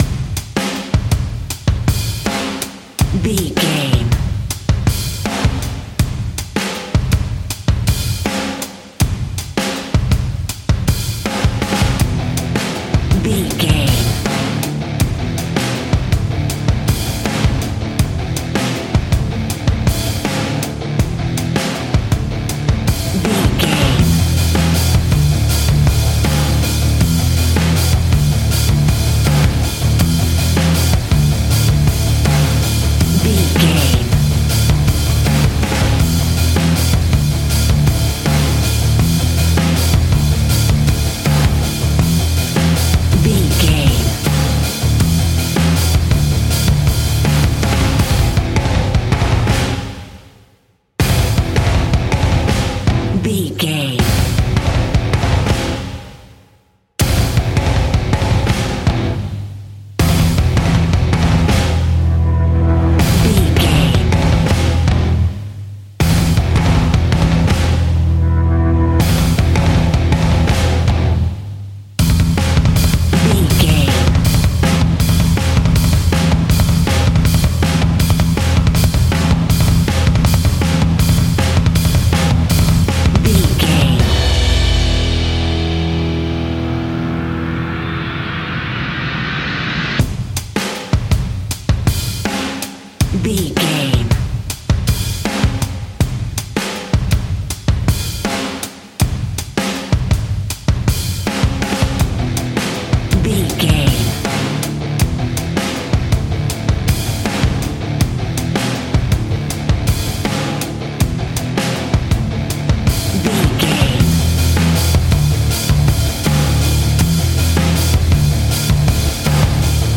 Ionian/Major
E♭
hard rock
heavy rock
distortion
instrumentals